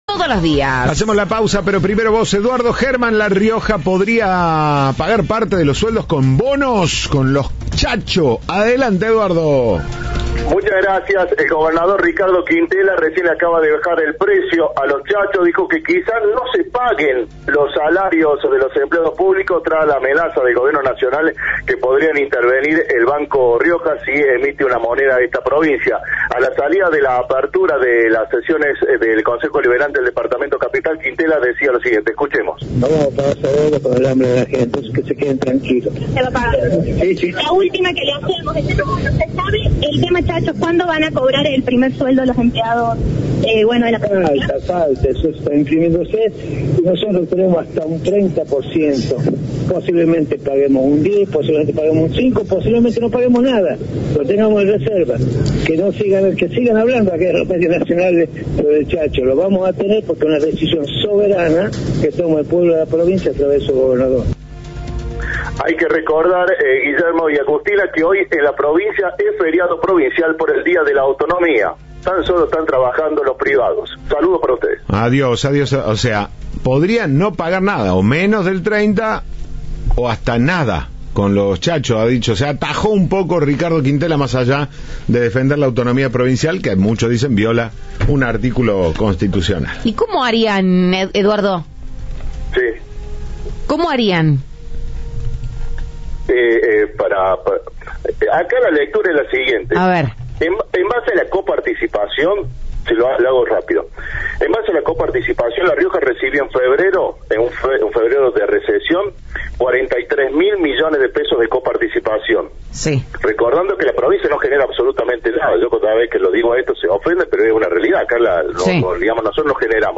"Posiblemente paguen un 10%, un 5% o posiblemente no paguemos nada", expresó Ricardo Quintela a la salida de la apertura de sesiones del Concejo Deliberante de este viernes.